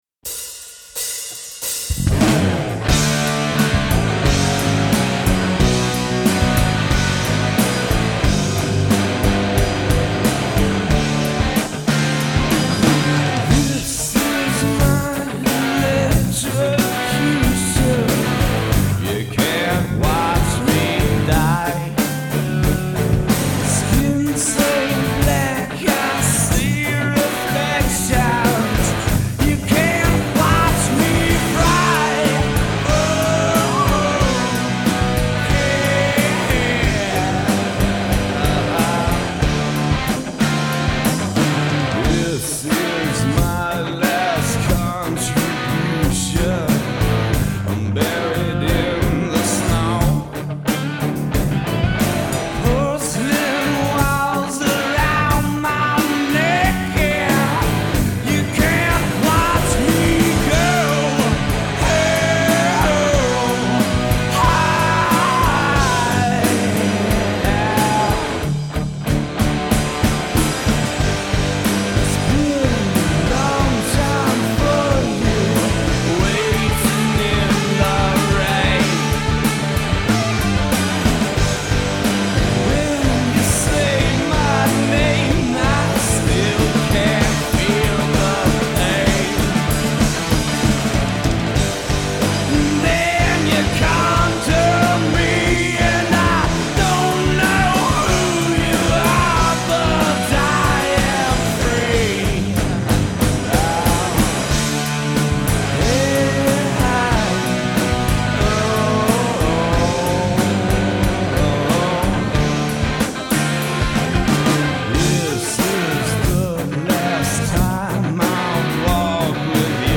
LIVE IN 09!